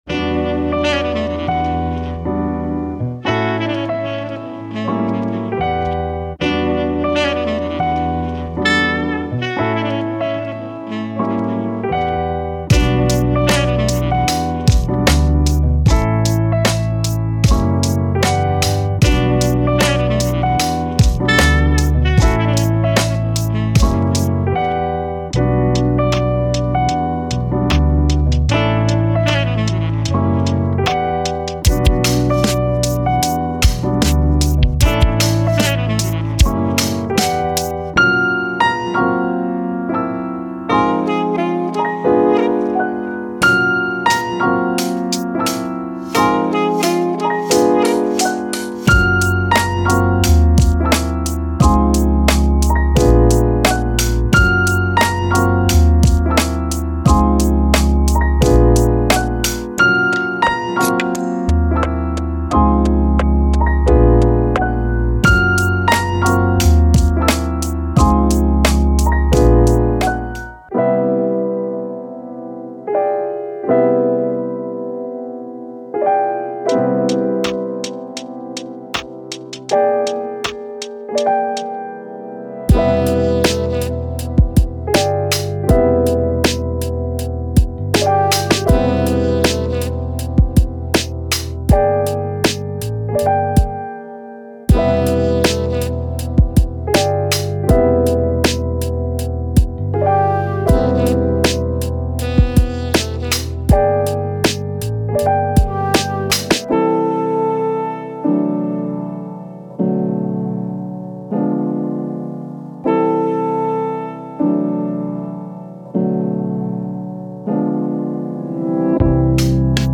パック内には、個性豊かなジャジーなテクスチャー、グルーヴ感あふれるドラム、そして本格的なジャズホップの雰囲気が満載。
デモサウンドはコチラ↓
Genre:Downtempo
10 Bass Loops
5 Flute Loops
10 Sax Loops
10 Piano Loops